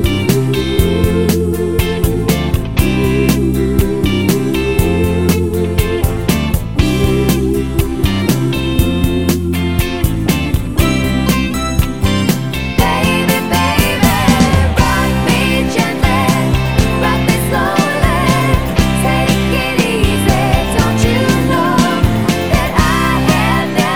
Professional Pop (1970s) Backing Tracks.